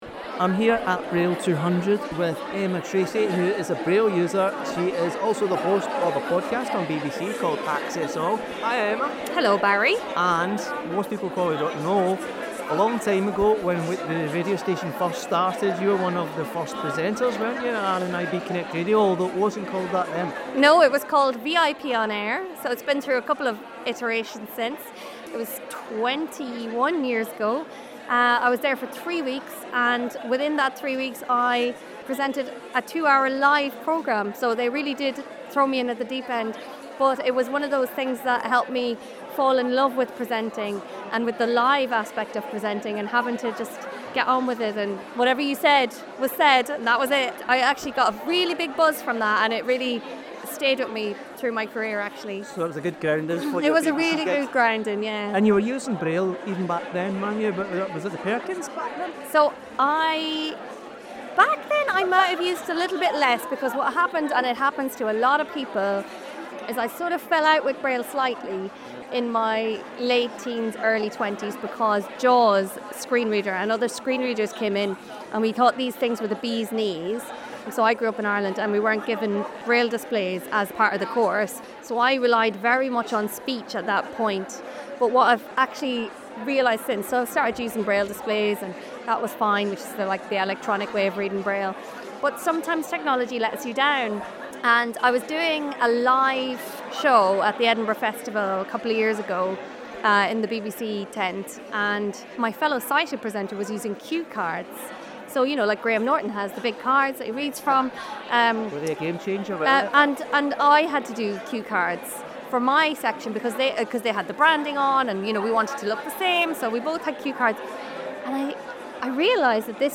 RNIB recently held an event to celebrate the bicentenary of the invention of braille. The Scottish Parliament reception, organised by RNIB Scotland, marked 200 years of the tactile code which enables blind and partially sighted people to read and write.